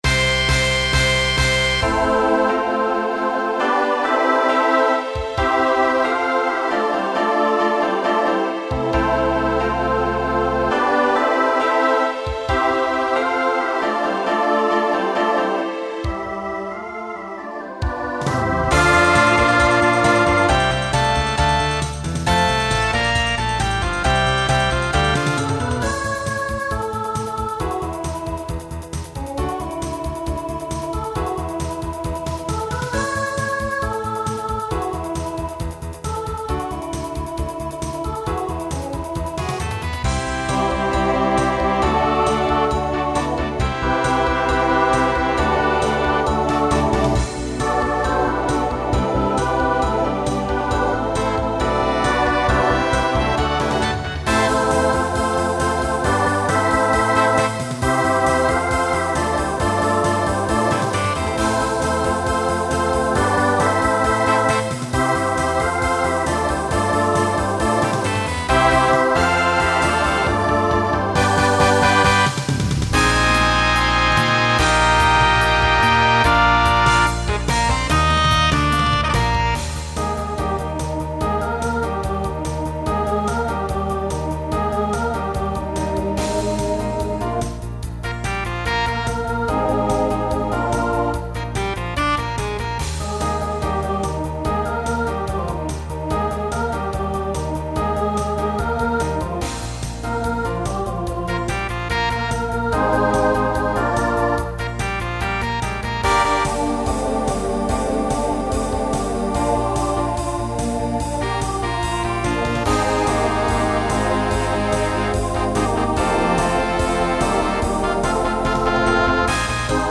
Genre Rock Instrumental combo
Show Function Opener Voicing SATB